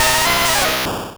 Cri de Racaillou dans Pokémon Rouge et Bleu.